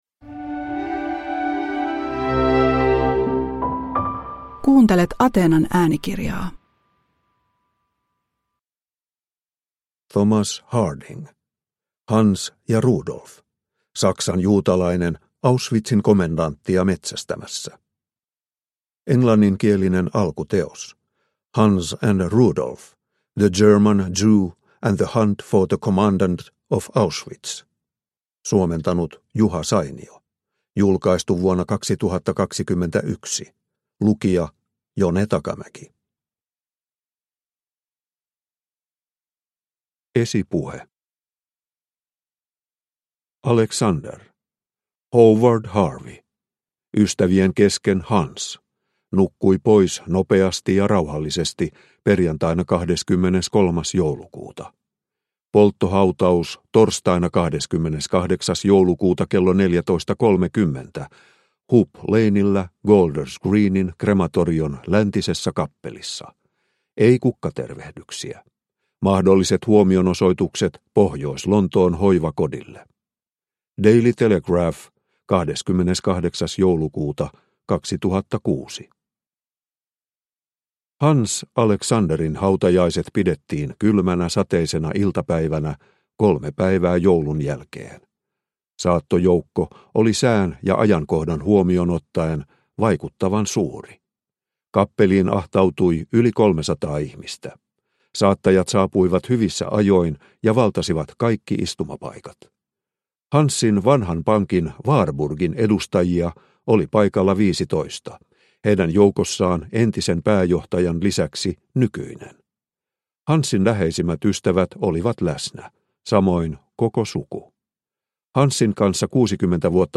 Hanns ja Rudolf – Ljudbok – Laddas ner